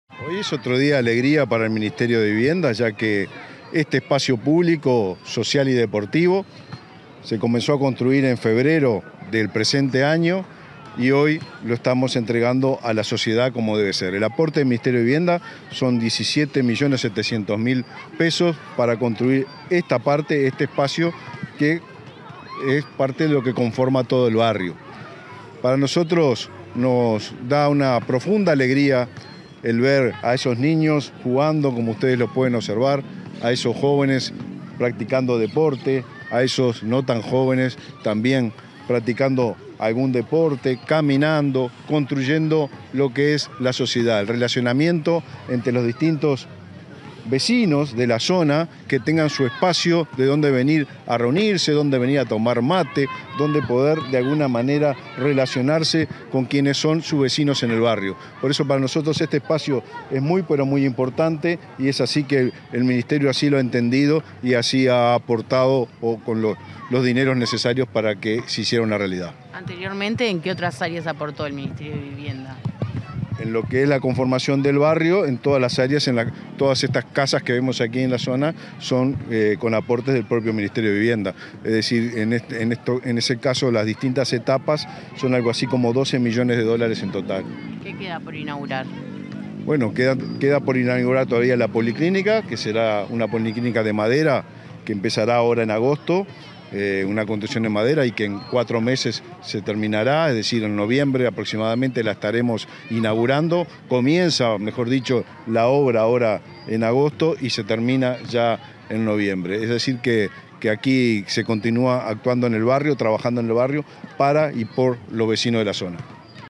Declaraciones del ministro de Vivienda y Ordenamiento Territorial, Raúl Lozano
Declaraciones del ministro de Vivienda y Ordenamiento Territorial, Raúl Lozano 16/07/2024 Compartir Facebook X Copiar enlace WhatsApp LinkedIn Tras la inauguración del espacio público en el barrio Cauceglia de Montevideo, este 16 de julio, el ministro de Vivienda y Ordenamiento Territorial, Raúl Lozano, realizó declaraciones a la prensa.